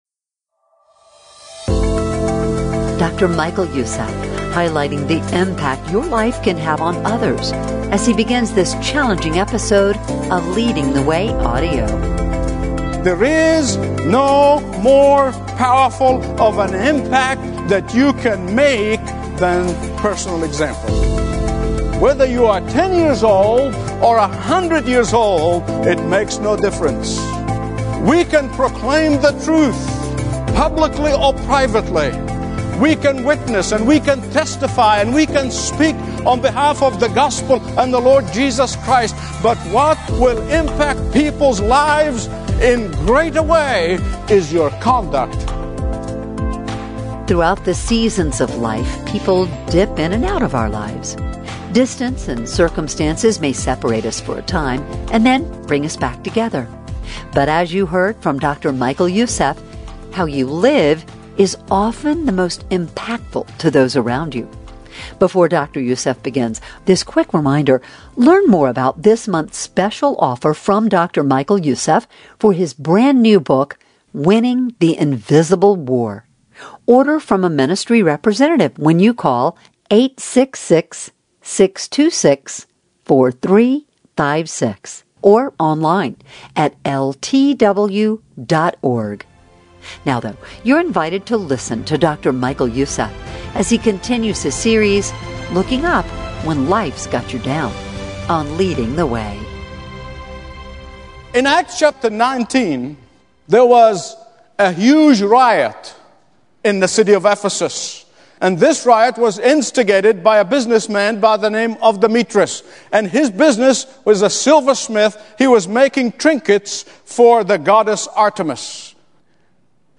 Preaching from Acts 20, he shows how the Apostle Paul lived with unwavering obedience, bold witness, and tear-filled urgency.